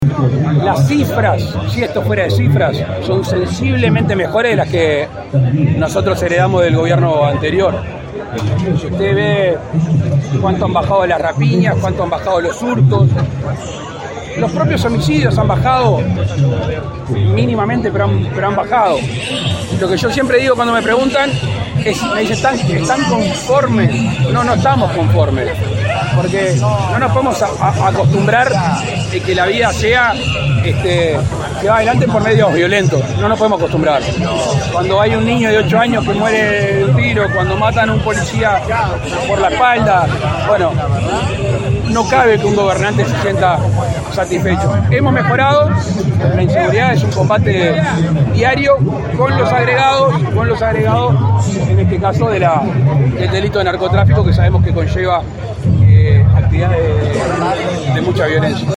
Estas declaraciones fueron realizadas en el departamento de Artigas, ya que el mandatario participó en el desfile de Carnaval.